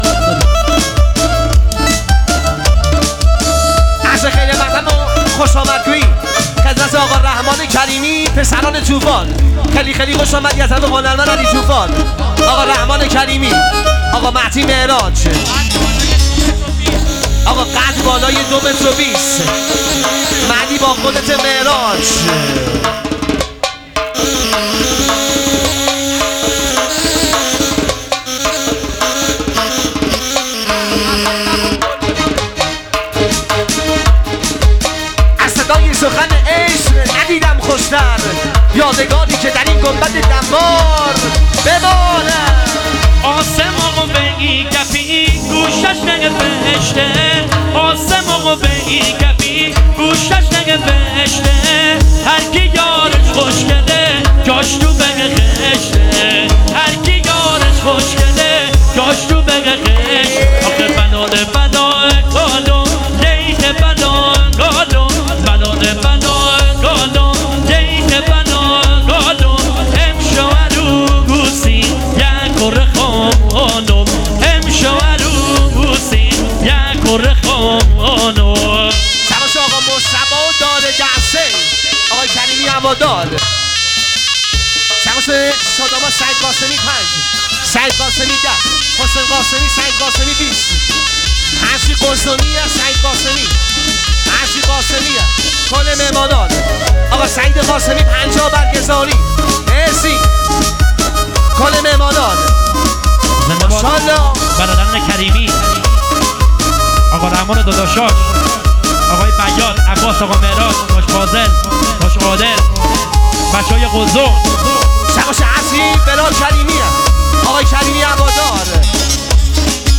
ترانه محلی لری